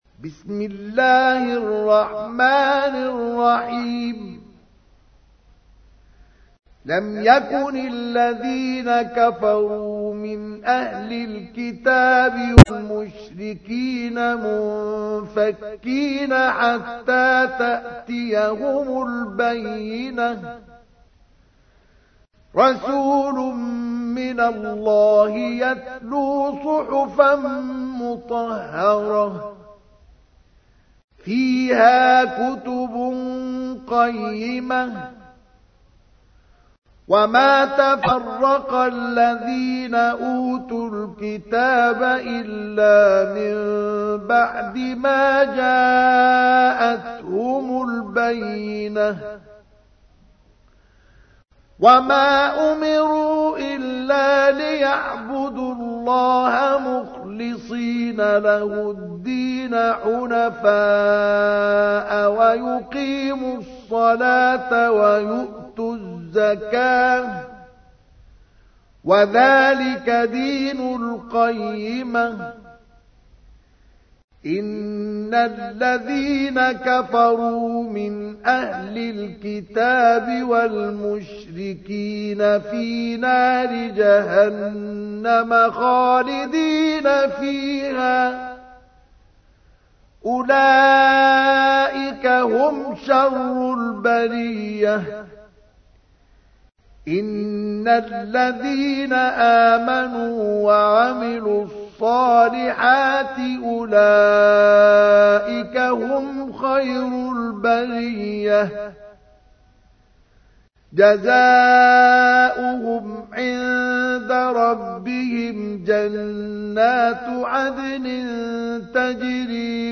تحميل : 98. سورة البينة / القارئ مصطفى اسماعيل / القرآن الكريم / موقع يا حسين